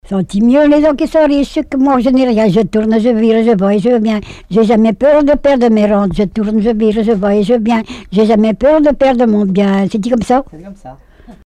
branle
Couplets à danser
collecte en Vendée
Répertoire de chants brefs et traditionnels
Pièce musicale inédite